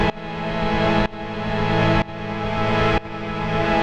Index of /musicradar/sidechained-samples/125bpm
GnS_Pad-MiscA1:2_125-A.wav